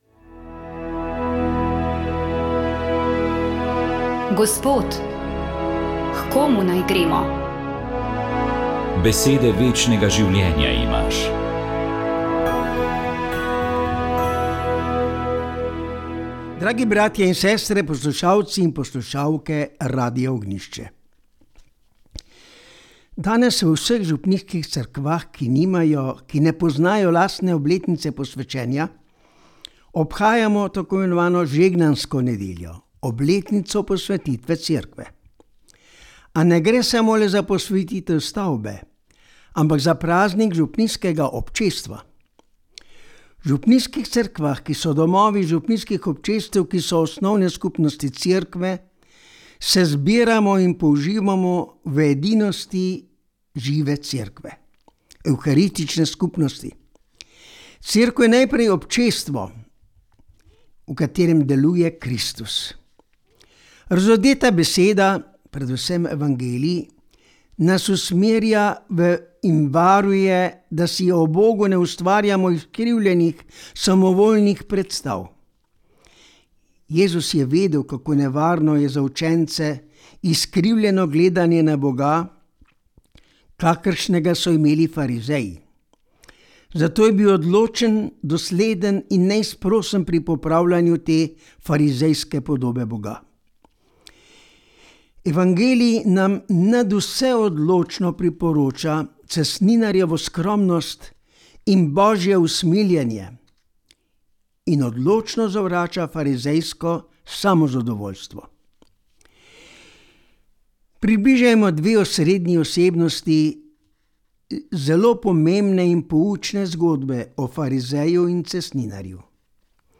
Duhovni nagovor
Nagovor je pripravil murskosoboški škof Peter Štumpf.